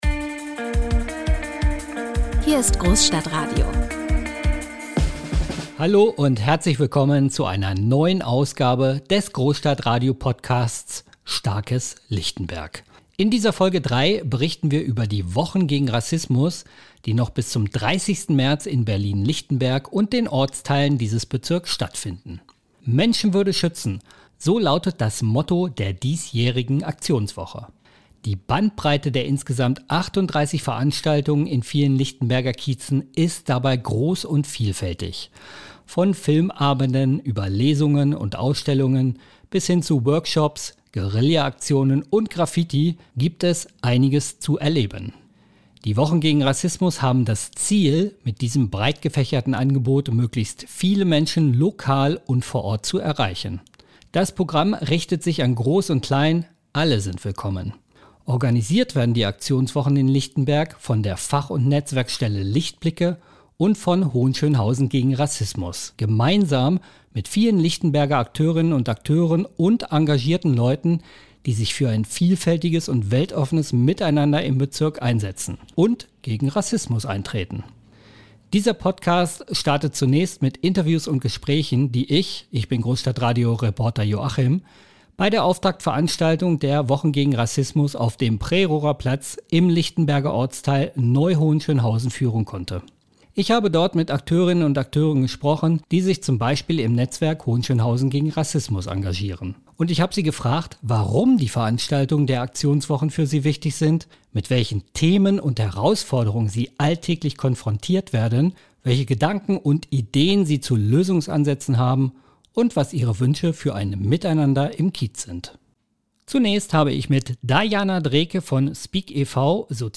Interviews und Gesprächen